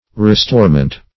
Restorement \Re*store"ment\, n.